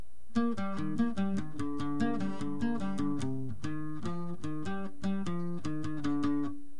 en Re. Presiona el Link y escucharas el requinto.!!
Intro: Re#x2